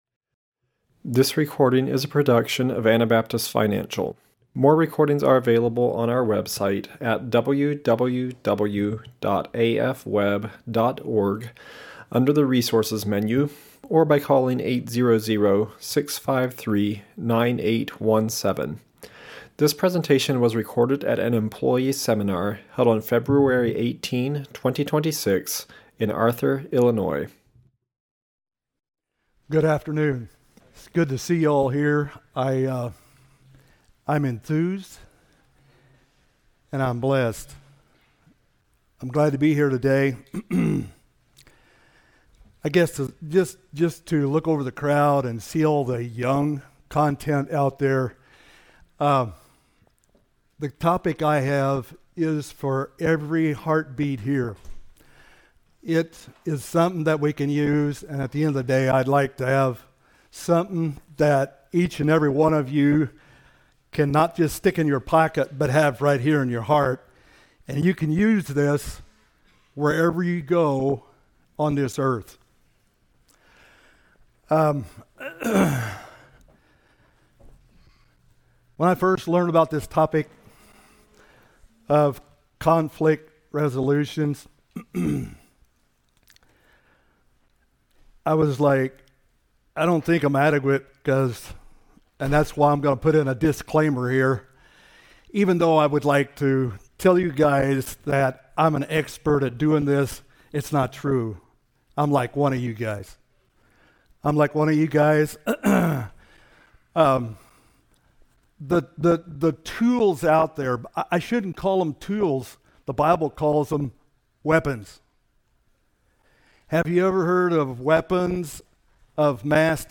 Illinois Employee Seminar 2026